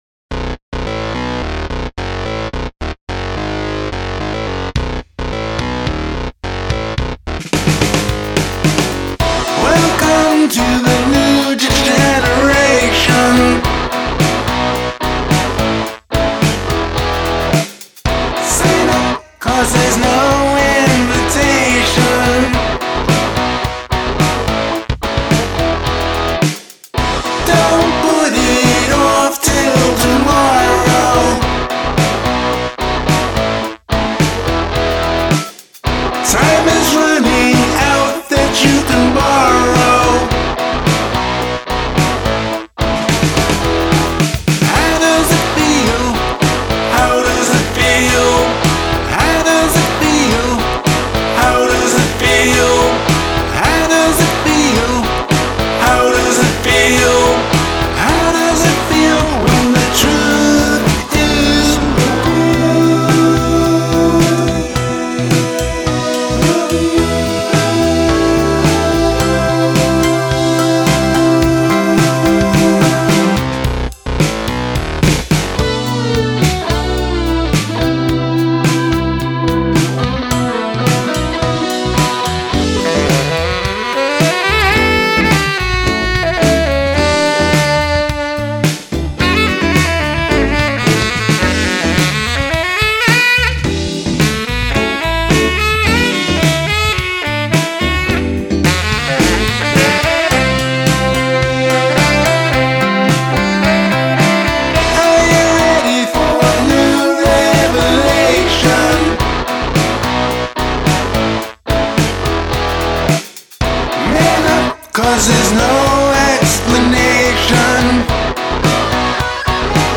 Ecclectic pop tune
This is the biggest project I've done so far on my DAW 46 channels.